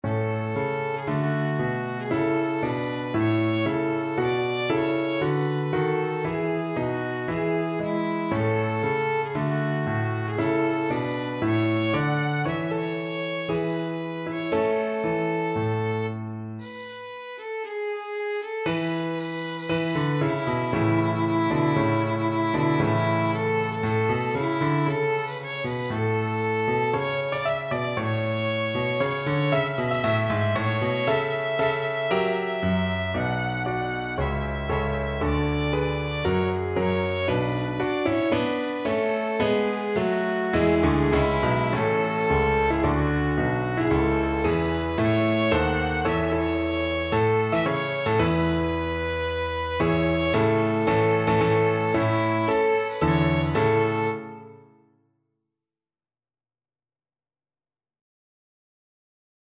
Violin
4/4 (View more 4/4 Music)
A major (Sounding Pitch) (View more A major Music for Violin )
March = c. 116
Traditional (View more Traditional Violin Music)